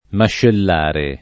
[ maššell # re ]